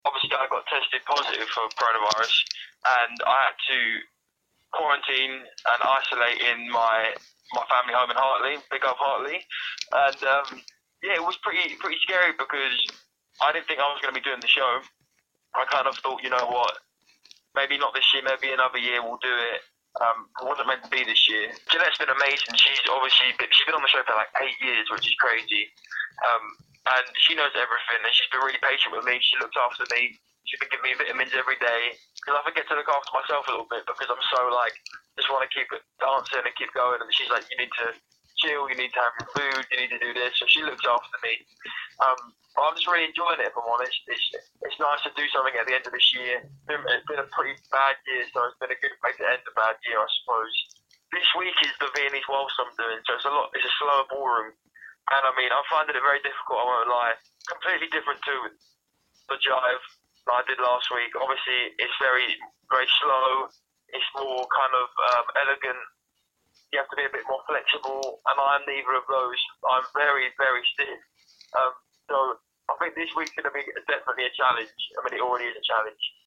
LISTEN: We spoke to HRVY ahead of his Strictly performance tonight 31/10/20